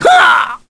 Mitra-Vox_Damage_kr_01.wav